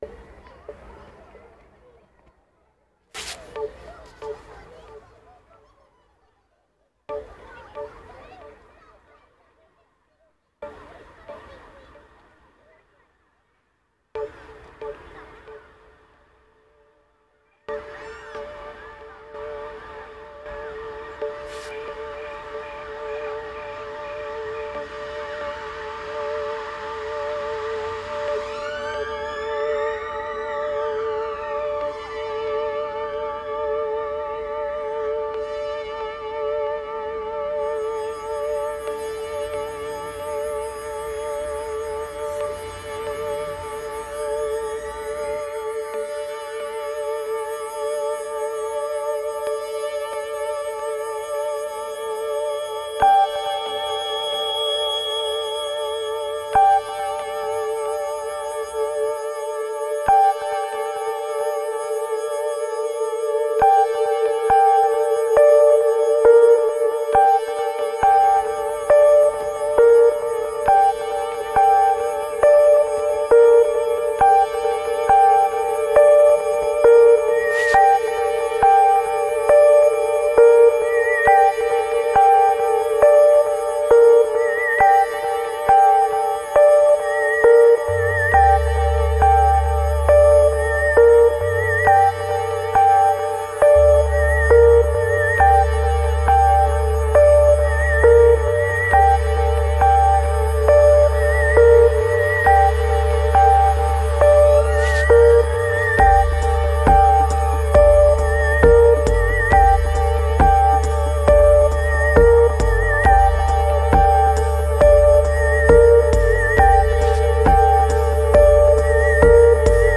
Fields and reels, more ambient than it should.
2907📈 - 66%🤔 - 68BPM🔊 - 2016-01-23📅 - 561🌟